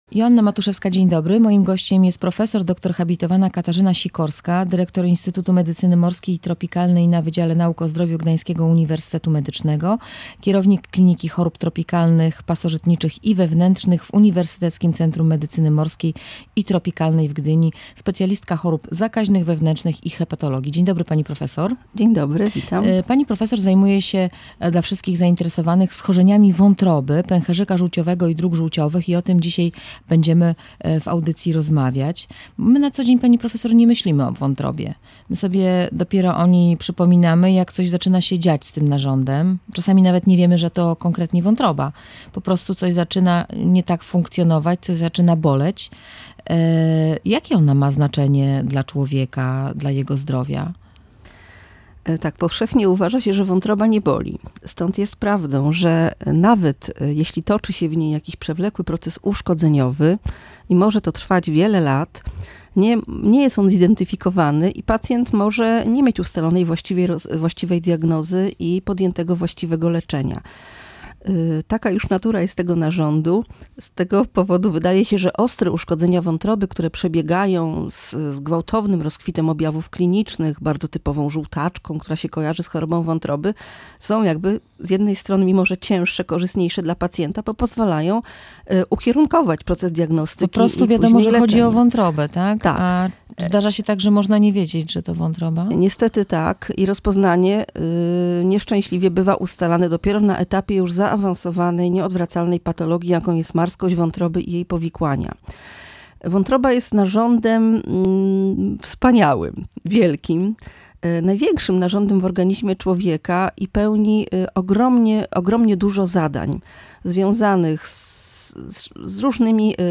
Między innymi o wątrobie, ale też pęcherzyku żółciowym i drogach żółciowych rozmawialiśmy w audycji „Radiowo-Naukowo”.